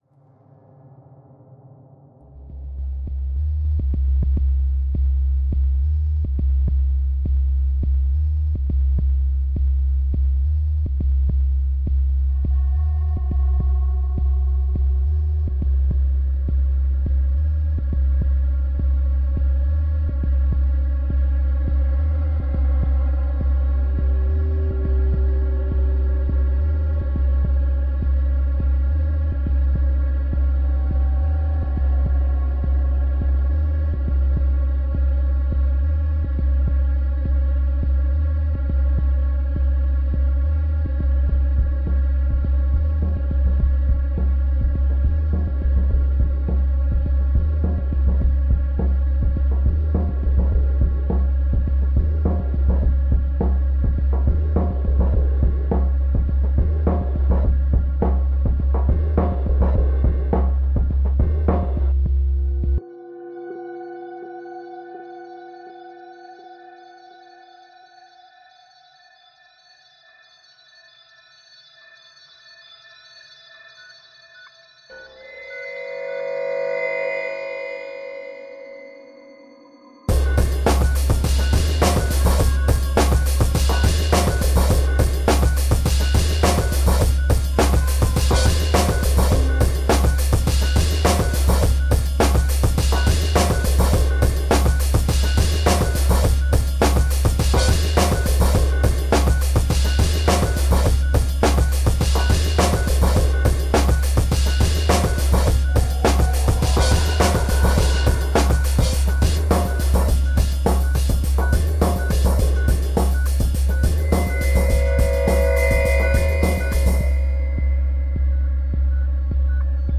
Download a clip of the score